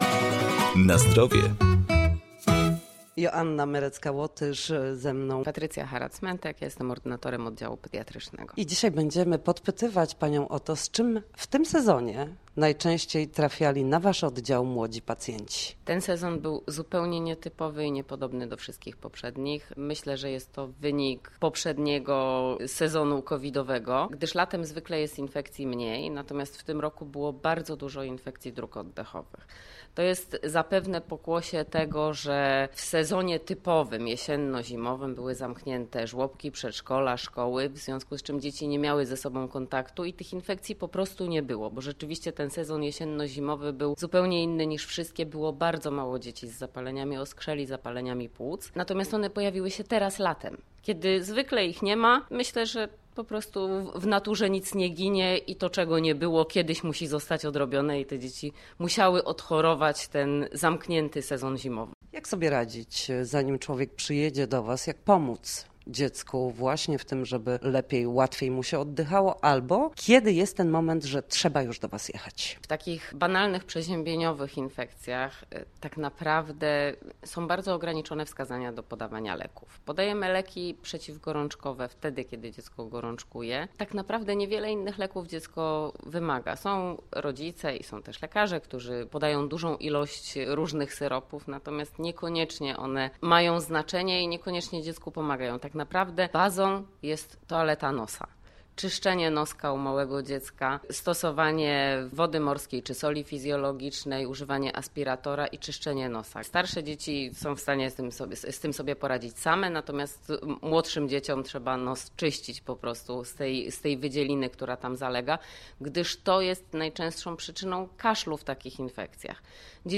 W poniedziałki o godzinie 7:20 na antenie Studia Słupsk przedstawiamy sposoby na powrót do formy po chorobach i urazach.
W audycji „Na Zdrowie” nasi goście, lekarze i fizjoterapeuci, odpowiadają na pytania dotyczące najczęstszych dolegliwości.